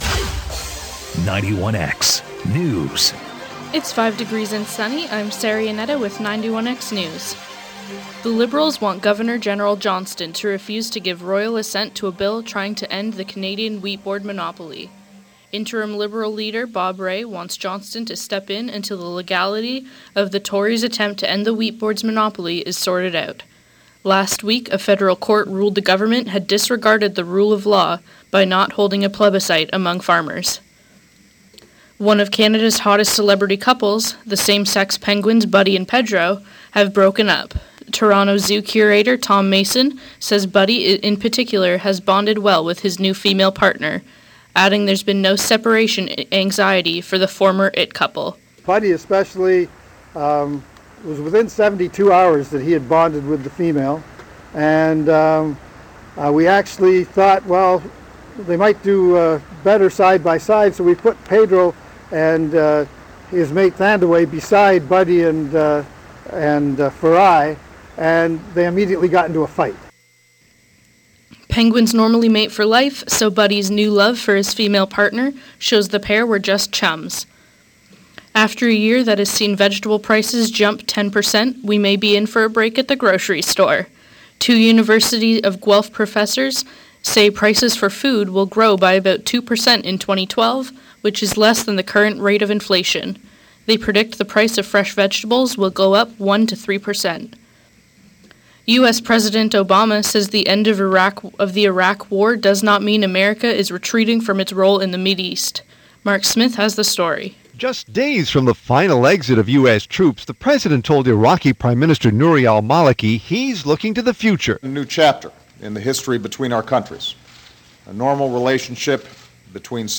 Liberals want Governor General David Johnston to refuse to royal assent to a bill to end the Canadian wheat board monopoly. American President Barack Obama says end of the Iraq war doesn’t mean it is walking away from its role in the Middle East and professors are gathering from Alaska to California to protest corporate greed. For these stories, sports and more listen to the 3 p.m. newscast.